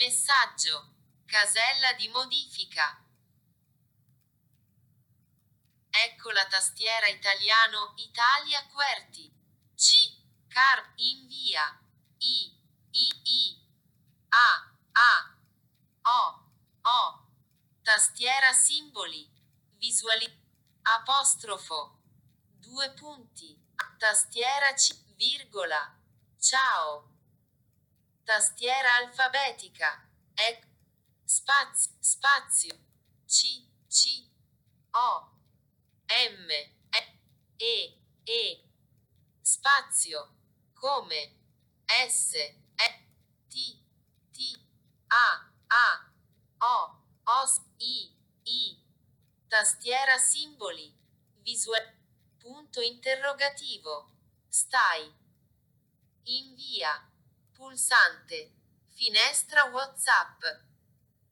Nota: Gli esempi audio sono relativi a Talkback su Android 12.0, ma con iOS il funzionamento è il medesimo.
Clicca Play di seguito per ascoltare la composizione di un messaggio con Talkback.
La prima volta che Talkback pronuncia il carattere è per indicare la posizione del dito sullo schermo, la seconda è perché è stato rilasciato il polpastrello e la lettera viene digitata.
Il tono di voce più acuto indica che la lettera è maiuscola.
Talkback-Scrittura-messaggio.mp3